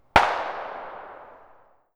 Pistol_ShootTail 01.wav